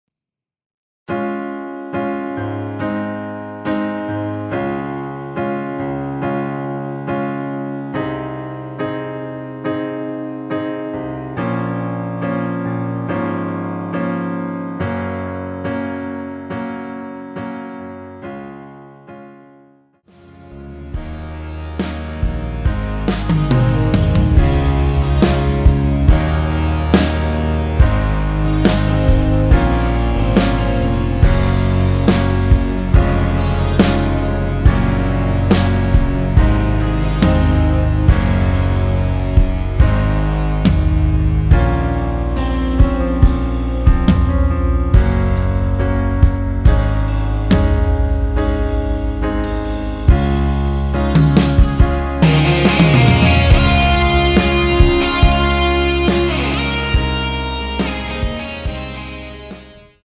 노래하기 편하게 전주 1마디 만들어 놓았습니다.(미리듣기 확인)
원키에서(-1)내린 MR입니다.
Db
앞부분30초, 뒷부분30초씩 편집해서 올려 드리고 있습니다.
중간에 음이 끈어지고 다시 나오는 이유는